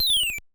fall.wav